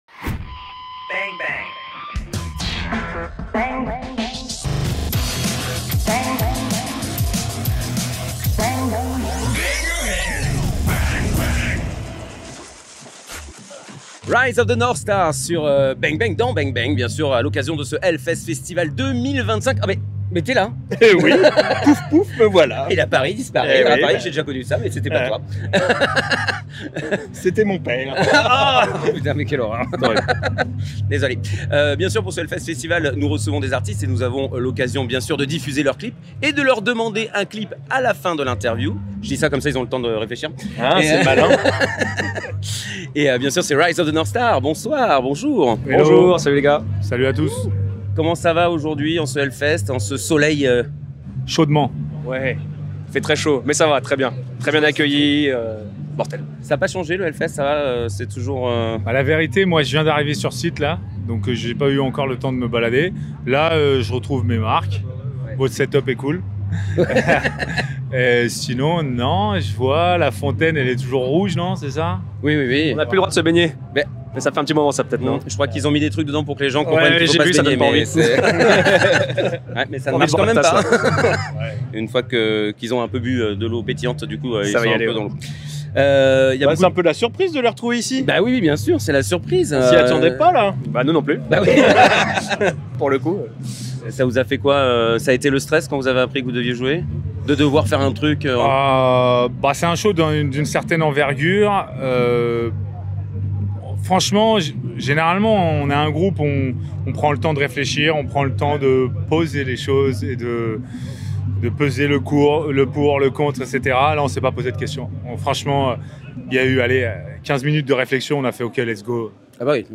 HORS SERIE HELLFEST '25 - RISE OF THE NORTHSTAR en invités - RSTLSS
Voilà encore une édition de HELLFEST accomplie ! Et nous avons fait 23 interviews !